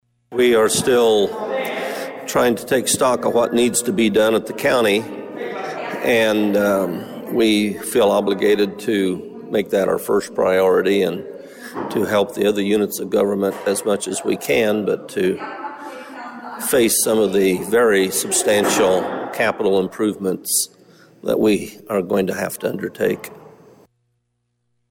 Steve Miller chairs the Finance and Personnel Committee of the County Board….